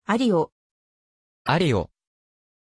Pronunciation of Ario
pronunciation-ario-ja.mp3